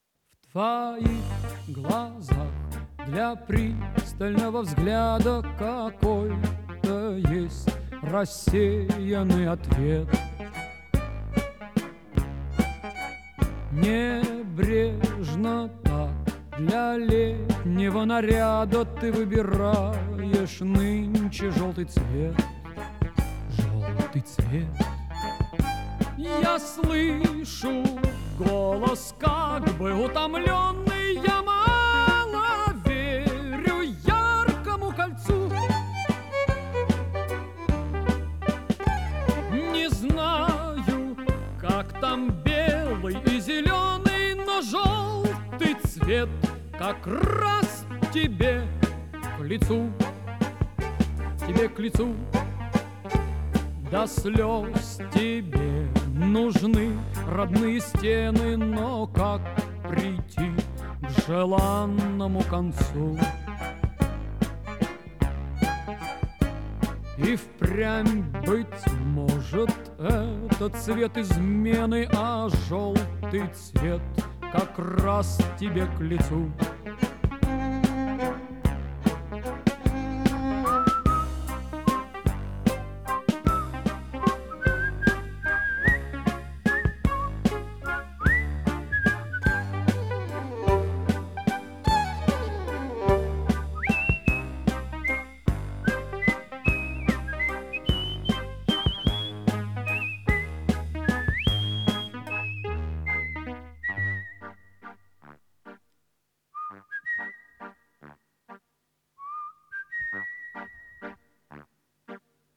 Жанр: Rock, Pop
Стиль: Art Rock, Vocal
Вокальная сюита